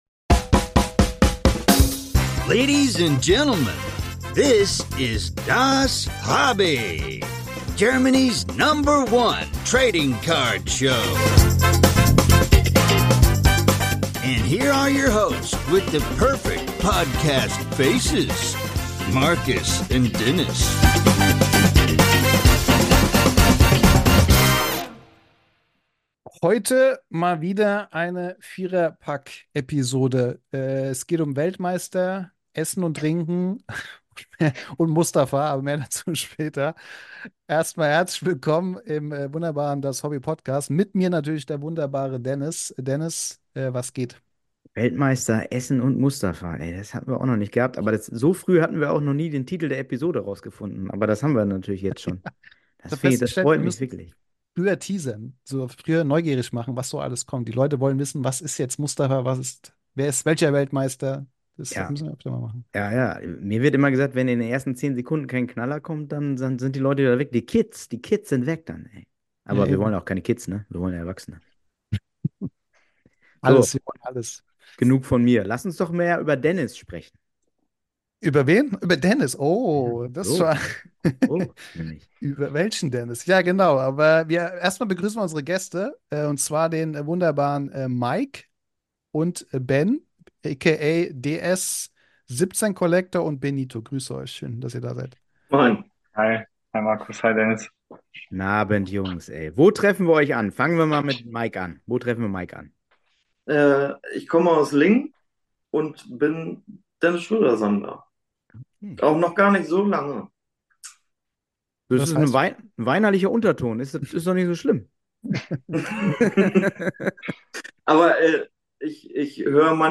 Beschreibung vor 1 Jahr Heute mal wieder in etwas größerer Runde mit zwei Gästen. Beide vereint die Sammelleidenschaft für Dennis Schröder und dazu haben sie auch eine schöne Kennenlernen-Geschichte zu berichten. Dazu quatschen wir über ihren Weg zu Dennis Schröder, Restaurantempfehlungen, die liebe Medienlandschaft und natürlich die Liebe zum Hobby.